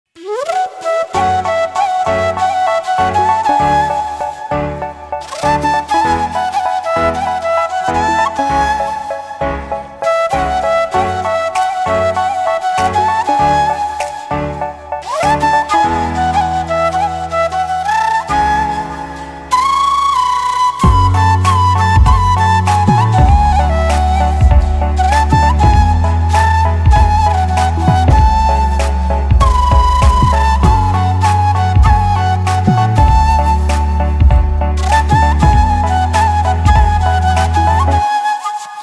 Kategorie Instrumentalny